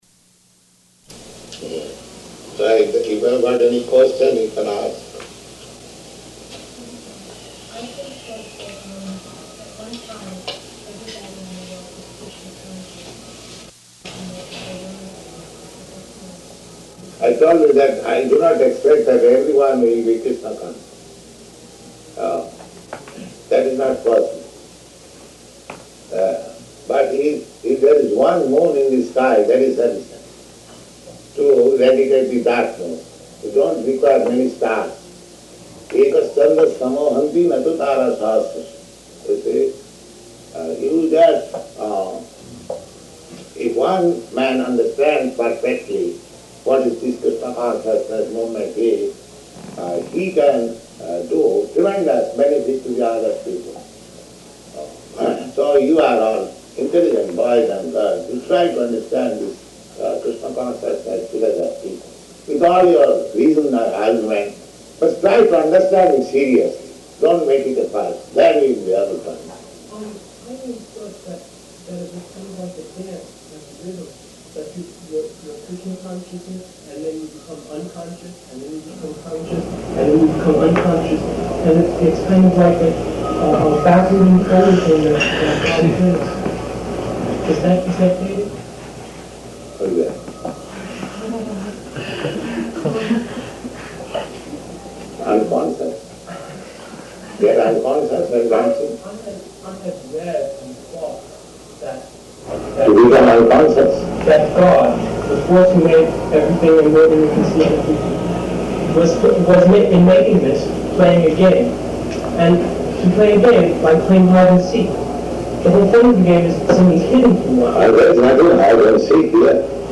Prabhupāda Listening to Recording of His Own Room Conversation with Students
Type: Conversation
Location: Boston